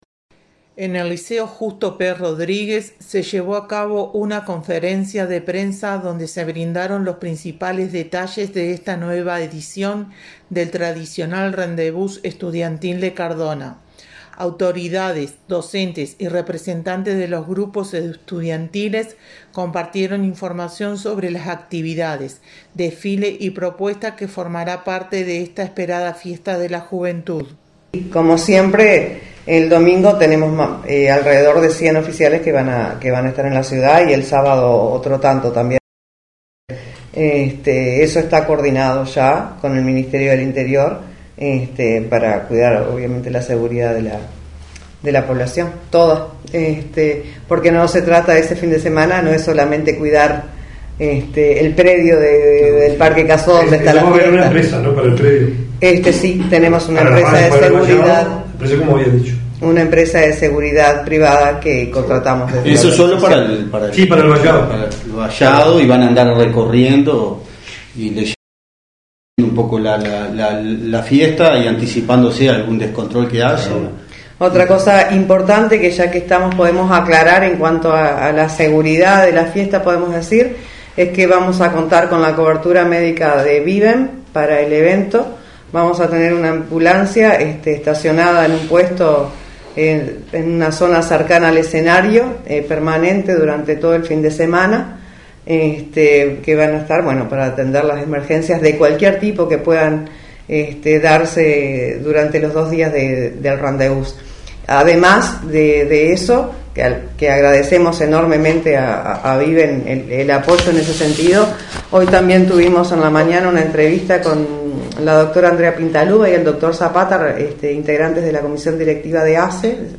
En el Liceo Justo P. Rodríguez se llevó a cabo una conferencia de prensa donde se brindaron los principales detalles de esta nueva edición del tradicional Rendez Vous Estudiantil de Cardona.
Autoridades, docentes y representantes de los grupos estudiantiles compartieron información sobre las actividades, desfiles y propuestas que formarán parte de esta esperada fiesta de la juventud.